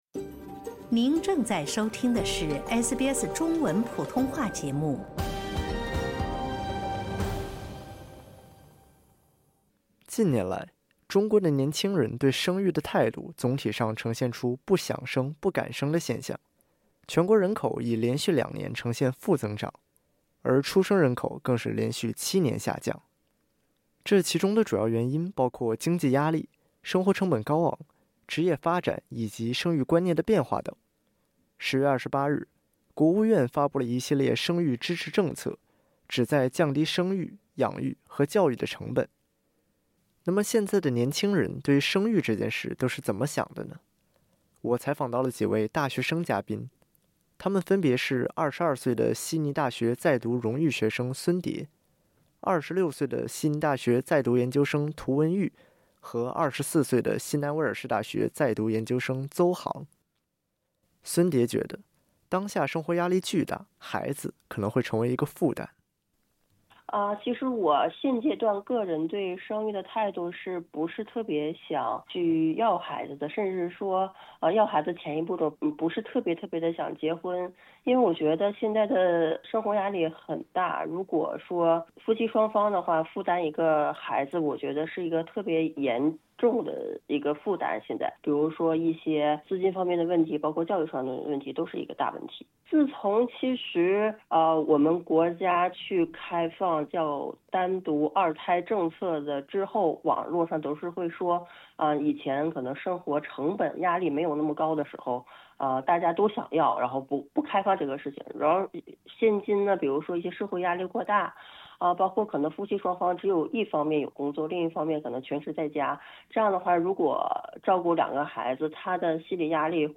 本台采访到了几位大学生嘉宾